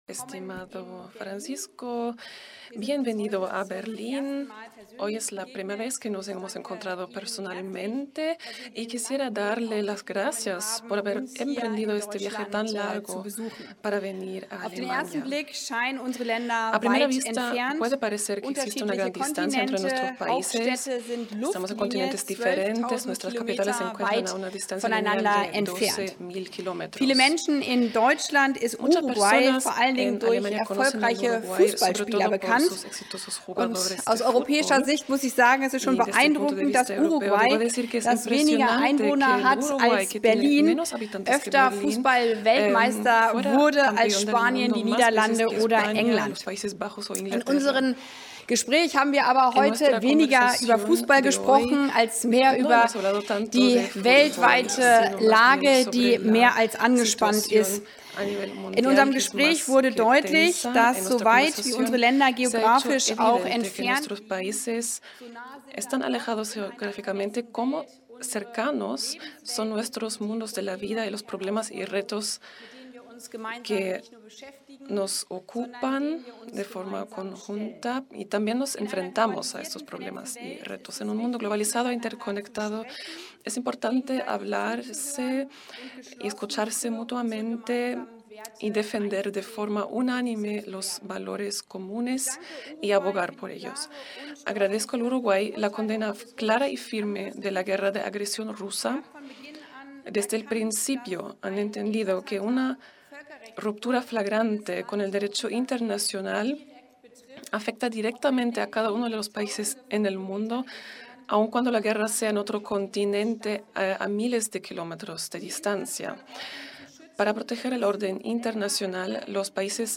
Conferencia de la ministra alemana Annalena Baerbock y el canciller Francisco Bustillo
Tras la reunión realizaron una conferencia de prensa conjunta, en la que destacaron las relaciones entre Mercosur y la Unión Europea y las bilaterales entre ambas naciones.